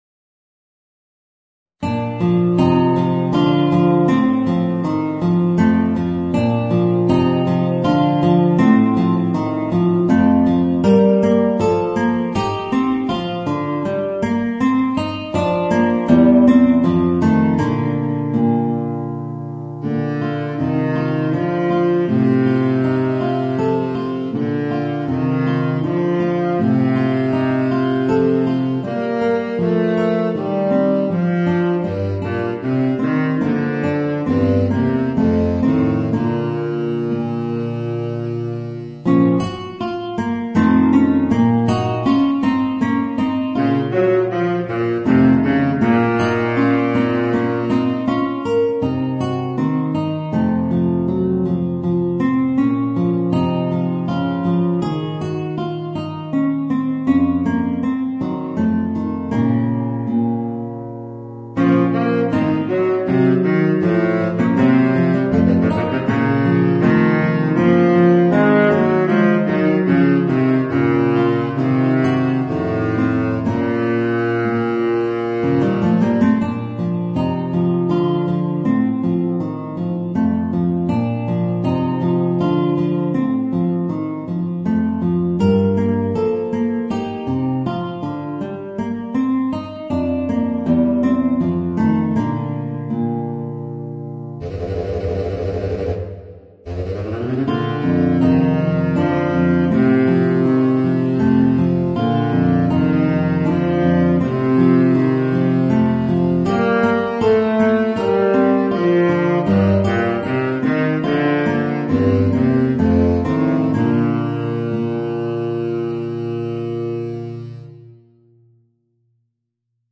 Saxophone baryton & guitare